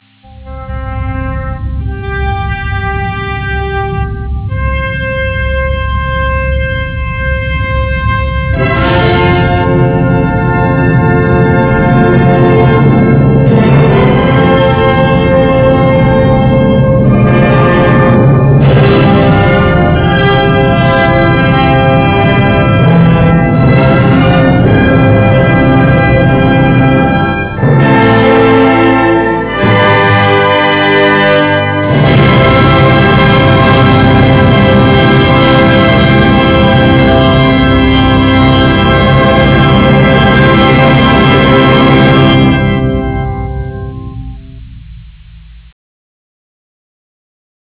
Original track music
Introduzione